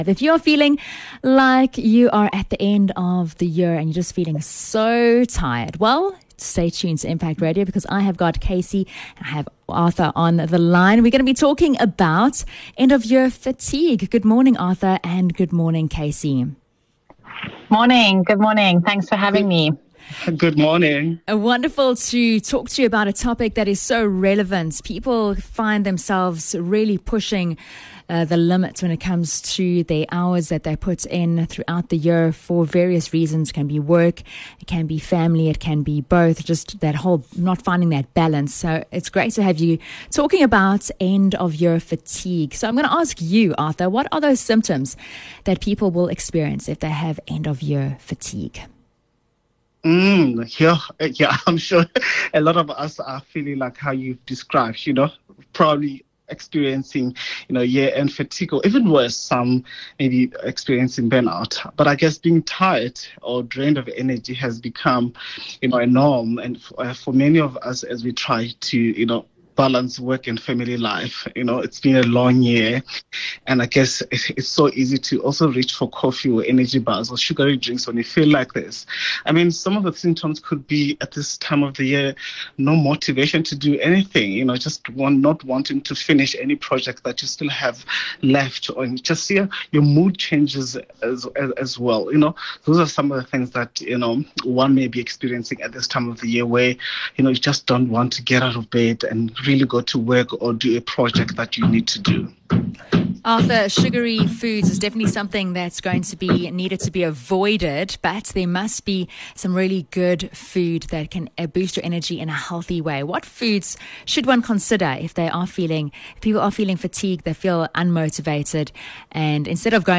14 Dec End Of Year Fatigue Interview